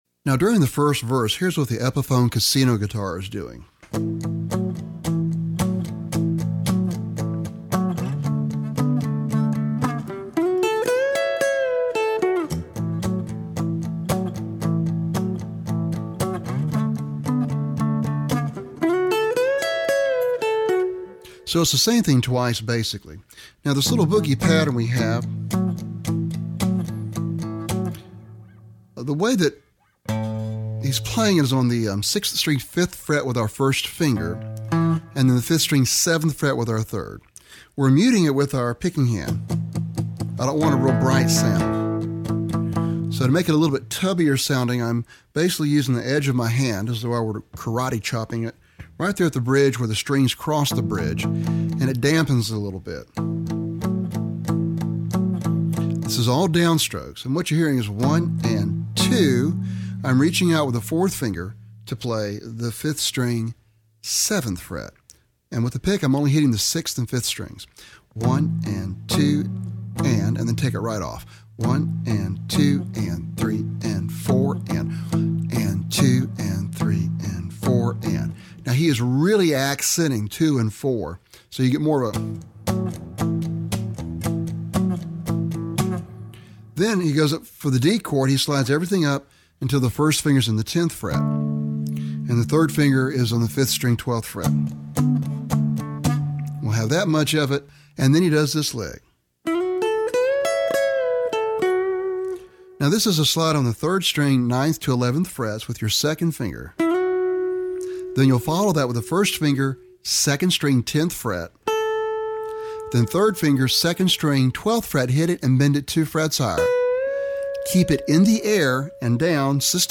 lesson sample